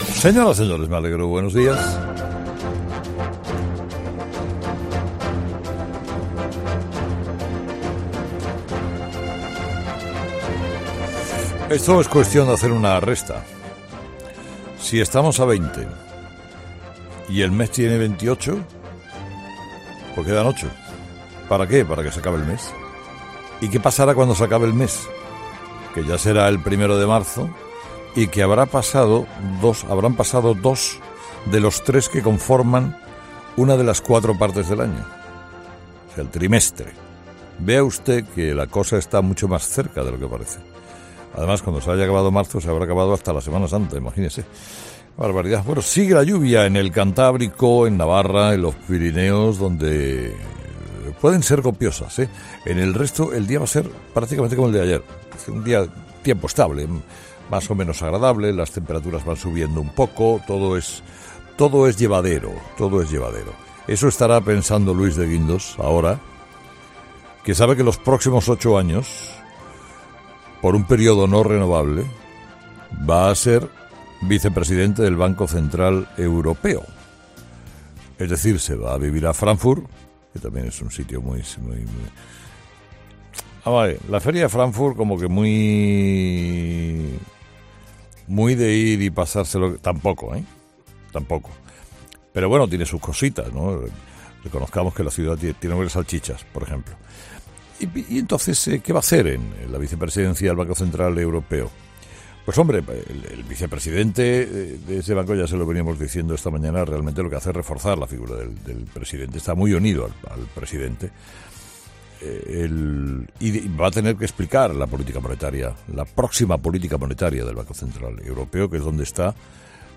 Monólogo de las 8 de Herrera 'Herrera en COPE